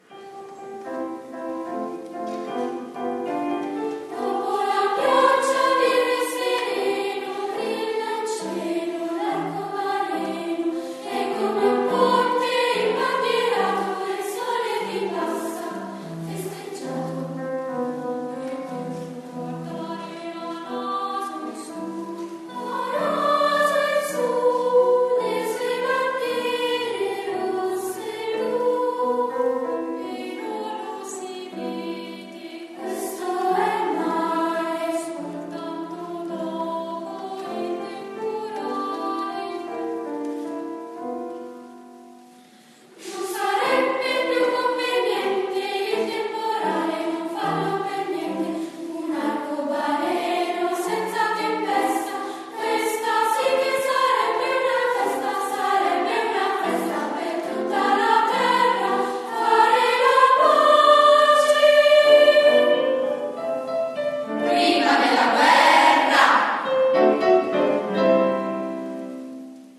coro di voci bianche